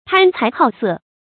贪财好色 tān cái hǎo sè
贪财好色发音
成语注音 ㄊㄢ ㄘㄞˊ ㄏㄠˋ ㄙㄜˋ